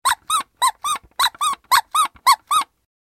パフーパフー（48KB）
マルチメディアカード記録済み効果音12種類